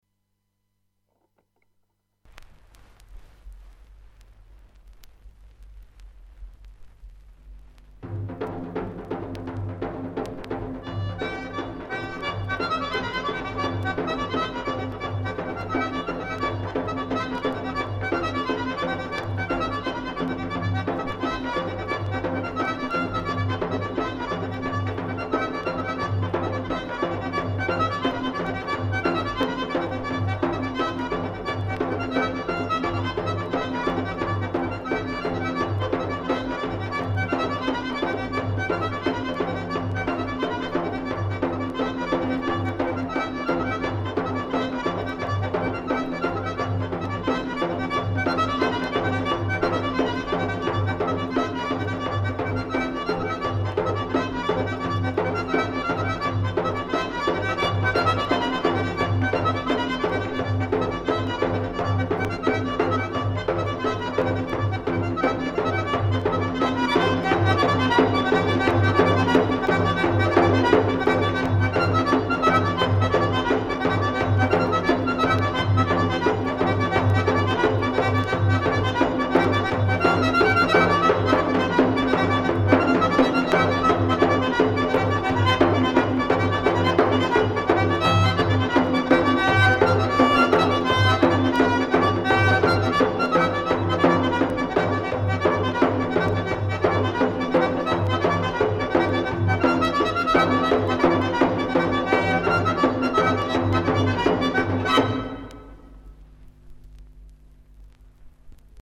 საკვანძო სიტყვები: ქართული ხალხური სიმღერა